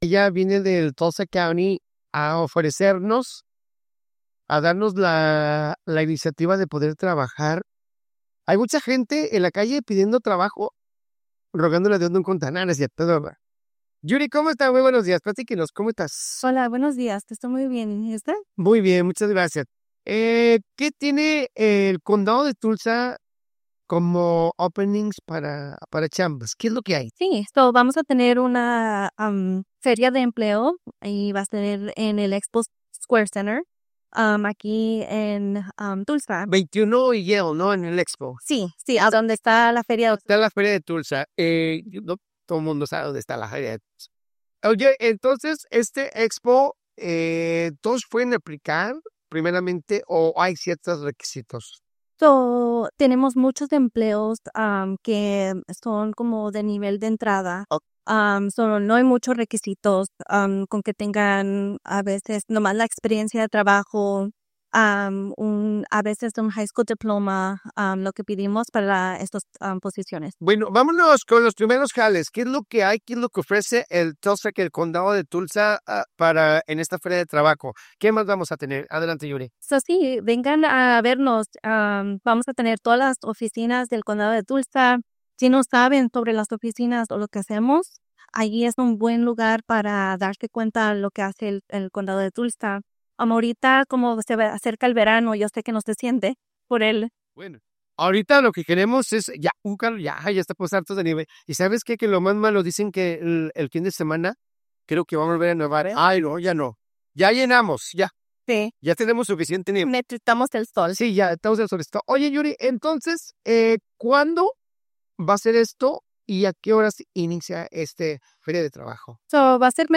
Durante una entrevista realizada hoy en nuestra estación de radio, se dio a conocer la próxima Feria de Empleo del Condado de Tulsa, un evento dirigido a personas que buscan incorporarse al mercado laboral o acceder a mejores oportunidades de empleo.